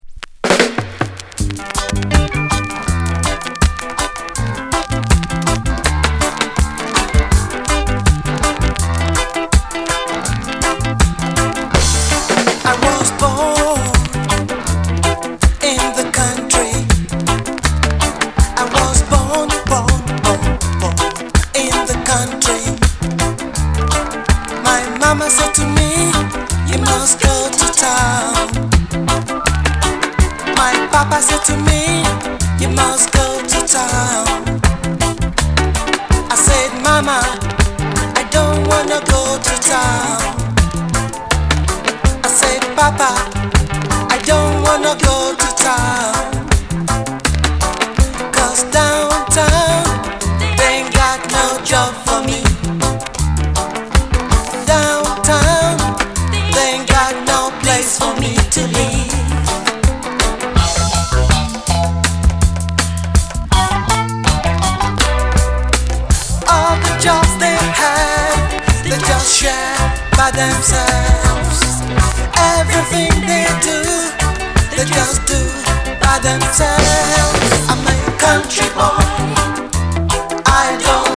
Genre:  Soul/Reggae